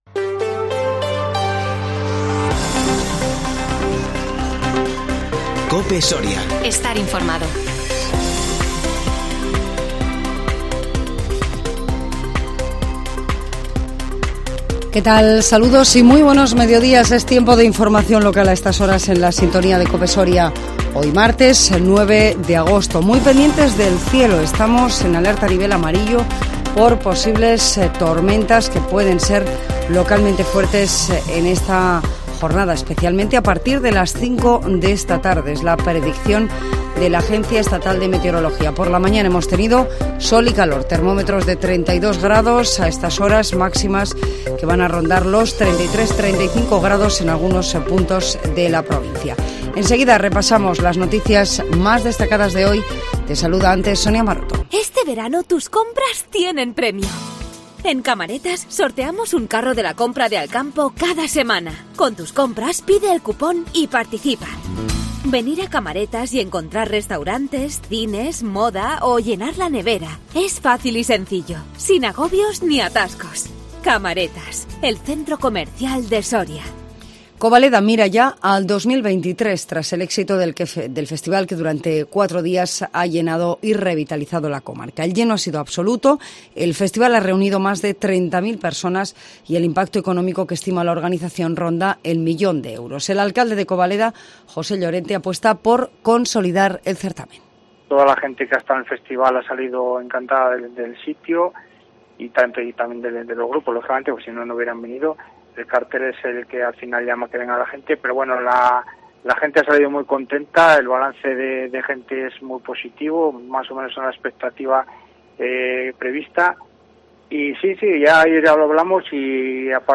INFORMATIVO MEDIODÍA COPE SORIA 9 AGOSTO 2022